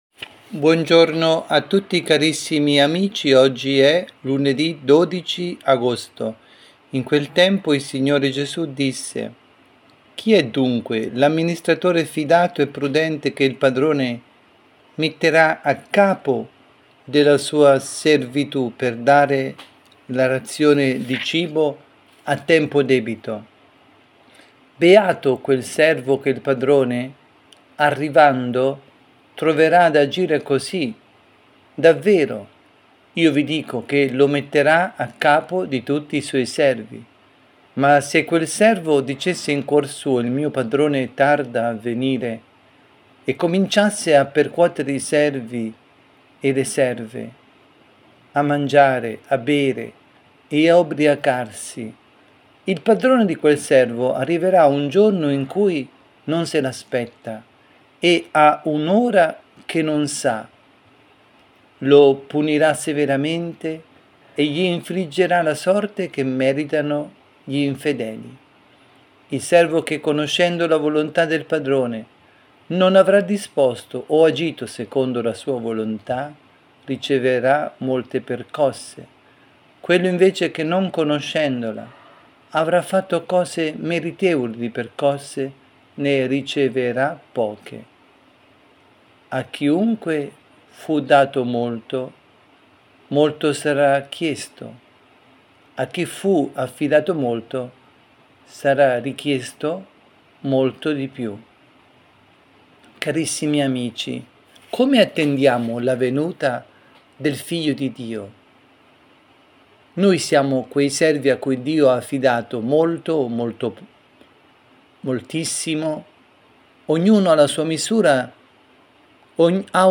avvisi, Catechesi, Omelie